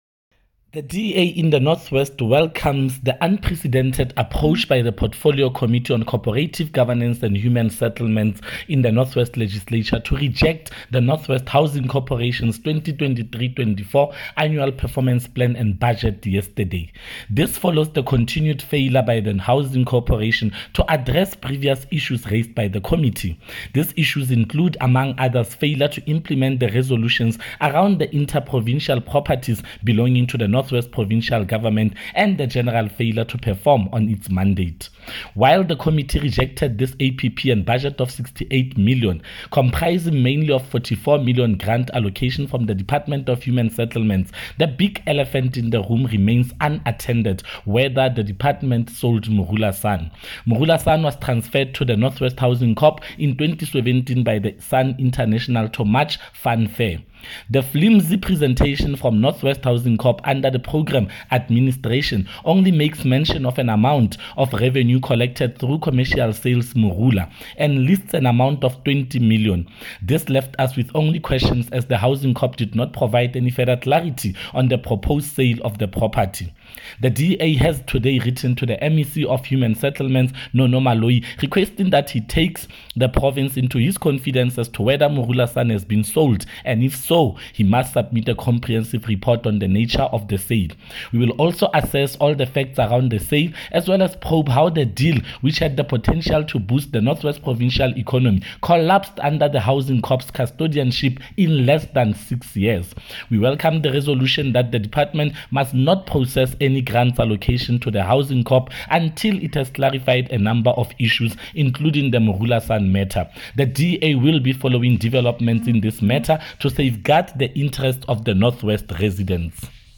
Note to Broadcasters: Please find attached soundbites in
Eng-Freddy-Sonakile-MPL-NW-Government.mp3